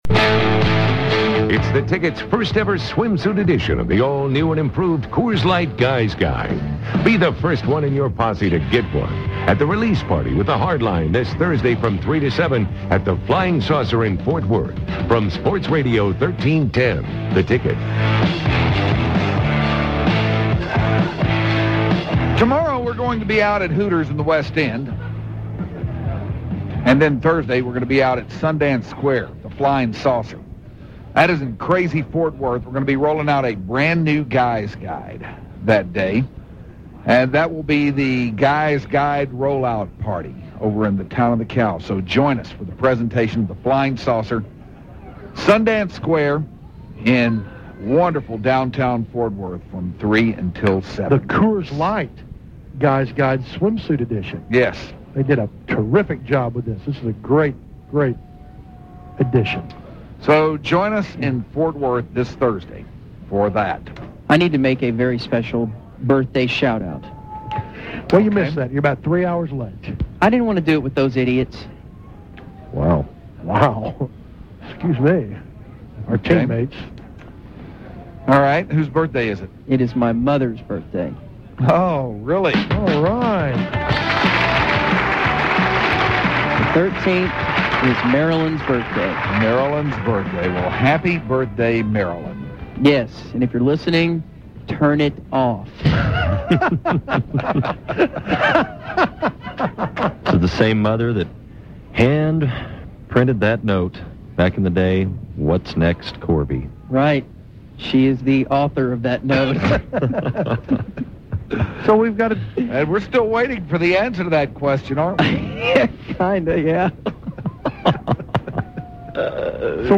The Hardliners host the Dallas Observer Music Awards at the Gypsy Tea Room